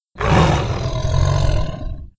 c_bslsk_dead.wav